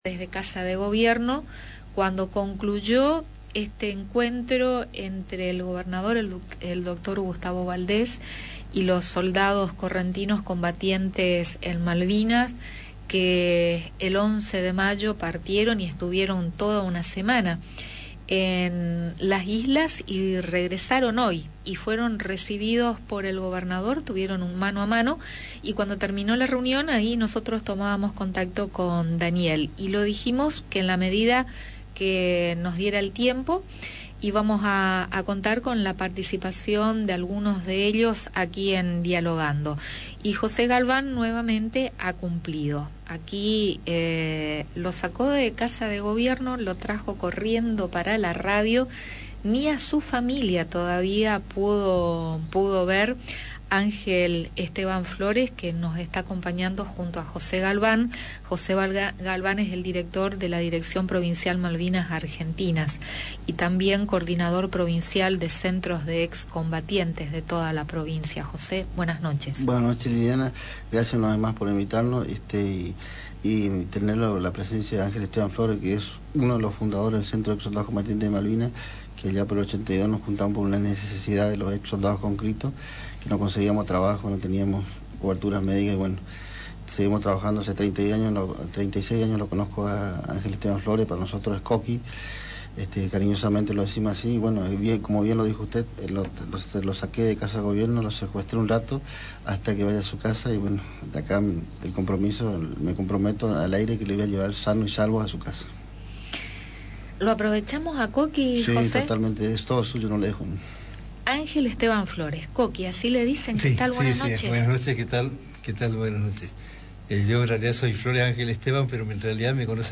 Con sus jóvenes 18 estuvo en el combate contra los ingleses y hoy pasó por los estudios de Radio Sudamericana para comentar su experiencia en este viaje de regreso, junto con otros 20 ex combatientes y 2 periodistas.